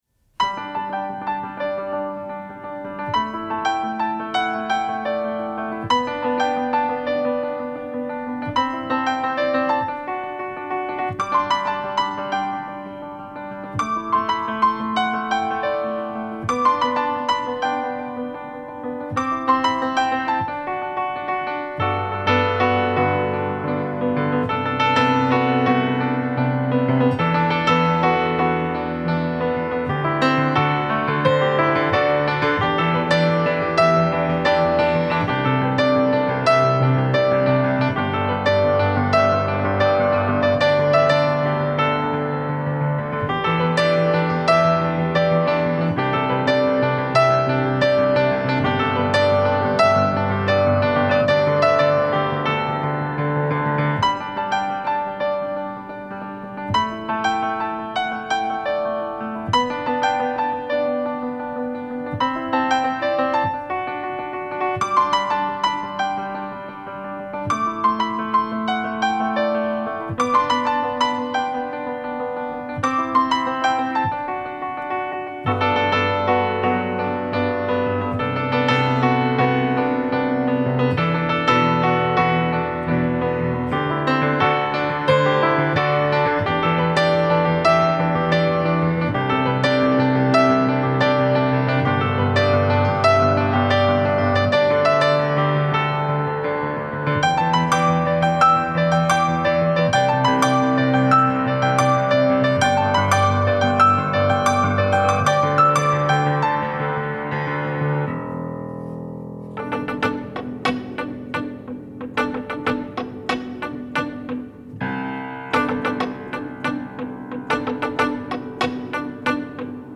Piano Track